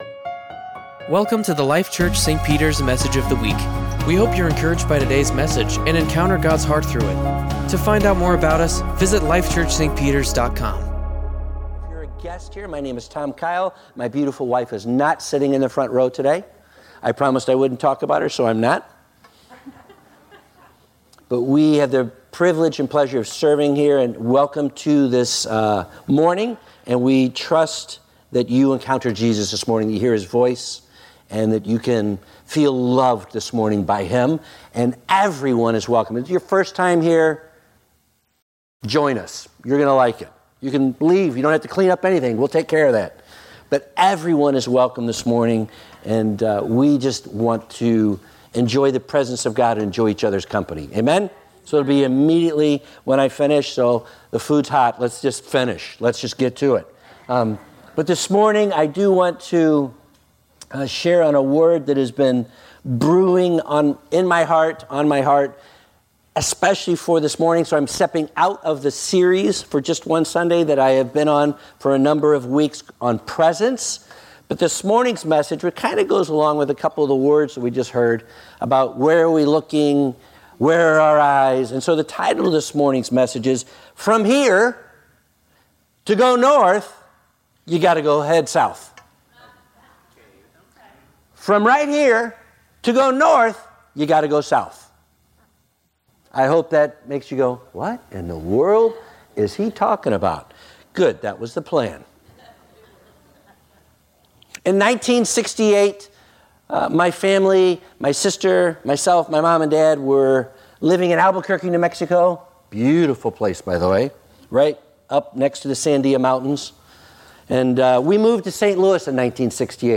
Sermons | Life Church - St. Peters